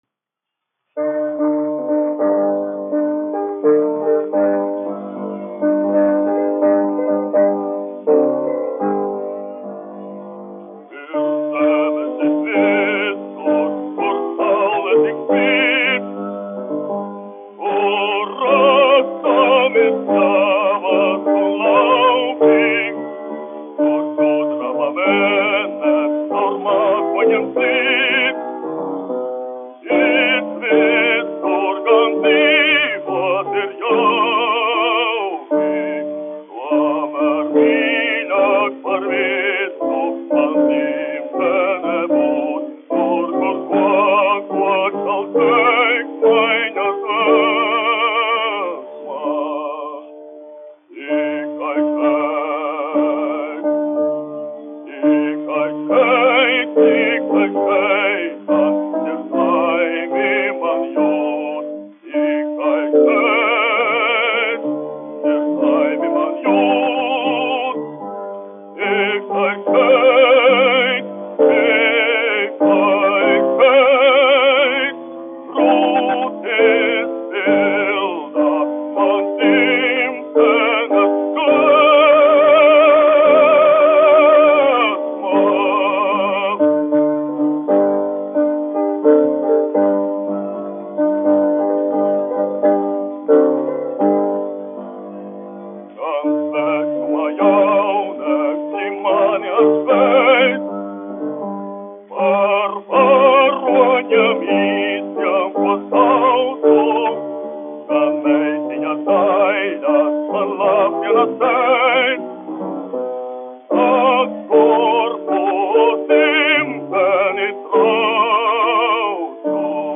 Kaktiņš, Ādolfs, 1885-1965, dziedātājs
1 skpl. : analogs, 78 apgr/min, mono ; 25 cm
Dziesmas (zema balss) ar klavierēm
Latvijas vēsturiskie šellaka skaņuplašu ieraksti (Kolekcija)